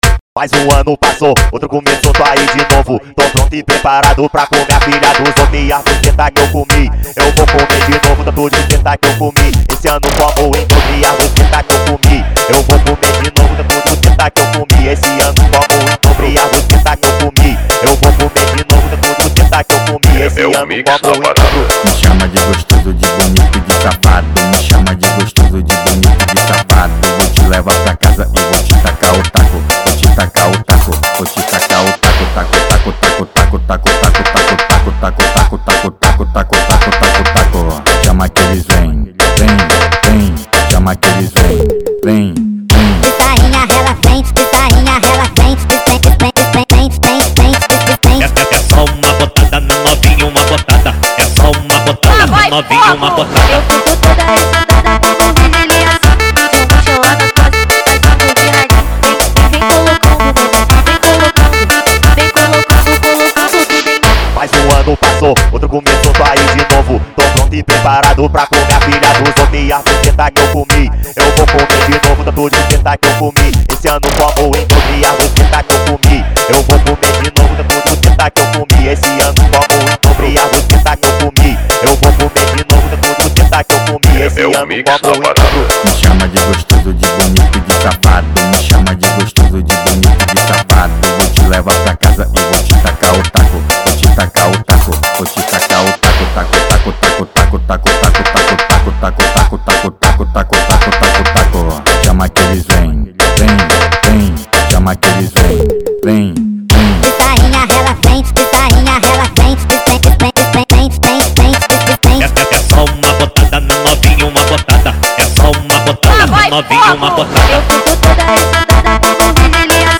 Tecno Melody